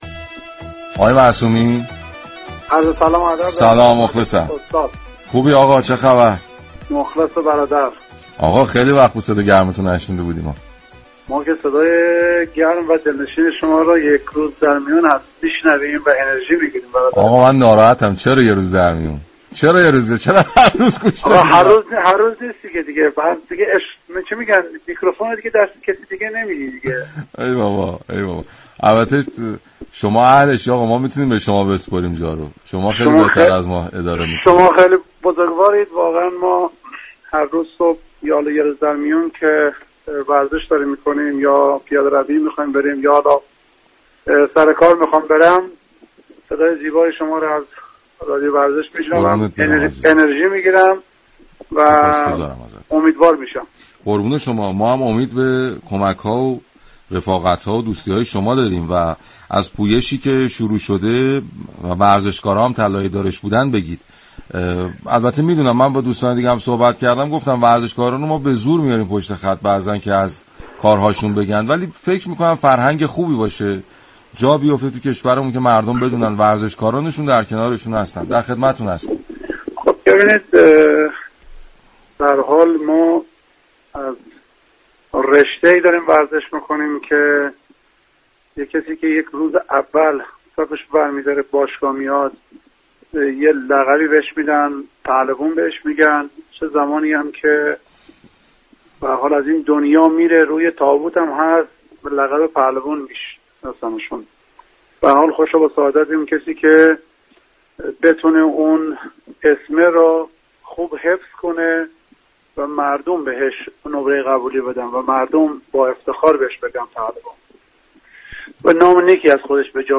فردین معصومی در گفتگو با رادیو ورزش